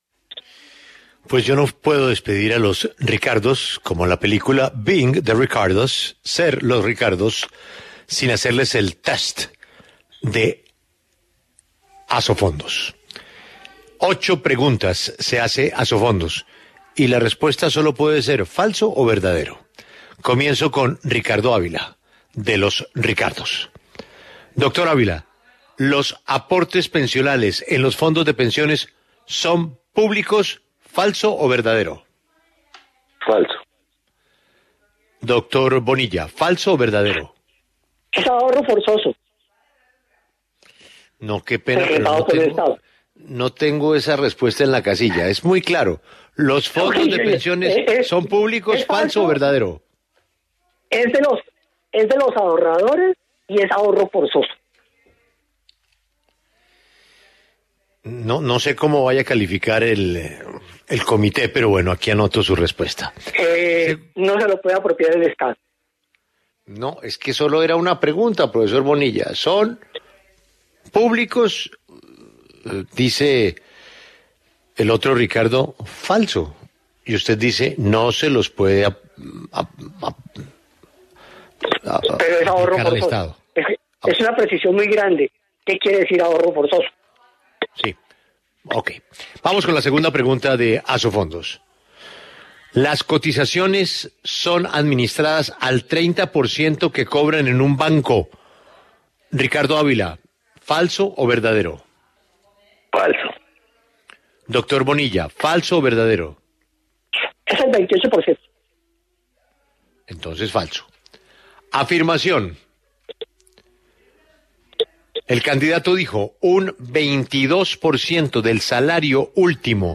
Por eso, para poner a prueba la viabilidad de la iniciativa, Julio Sánchez Cristo, director de La W, sometió a los analistas a las ocho preguntas formuladas por Asofondos.
Las preguntas, de respuesta falso o verdadero, fueron las siguientes: